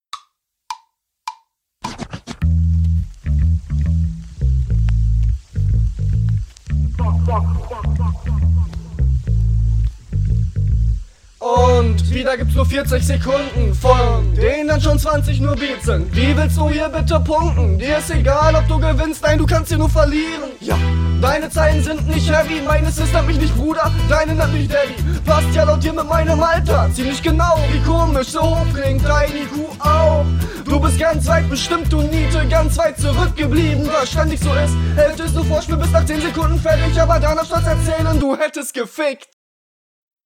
Flow ist auch bei dir schwächer als in deinen Vorrunden, allerdings konterst du eigentlich alles, …